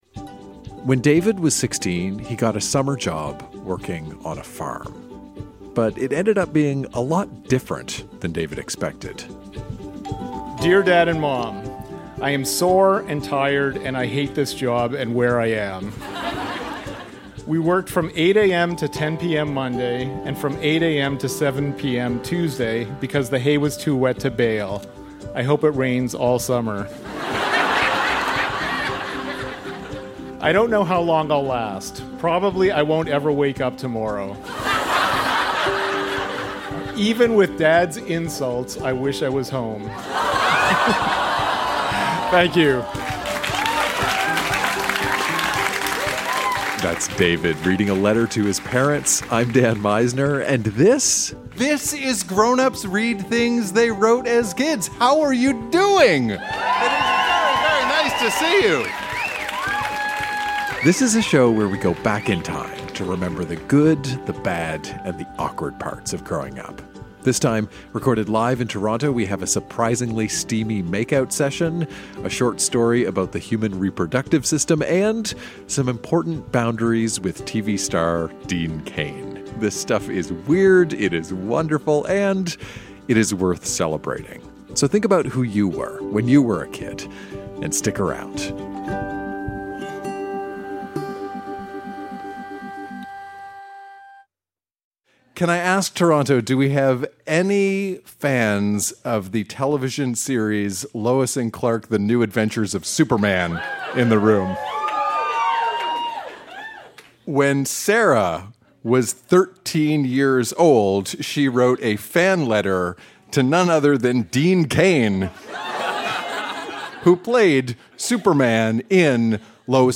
Recorded live at the Tranzac in Toronto.